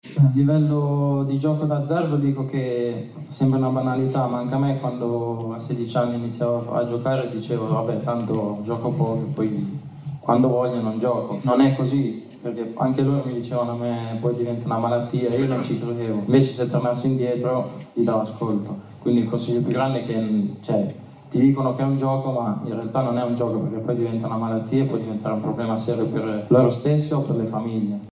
Avete appena ascoltato le parole di Nicolò Fagioli, calciatore della Juventus, squalificato per 7 mesi nell’ottobre del 2023 per aver effettuato scommesse su eventi calcistici, violando il Codice di Giustizia Sportiva.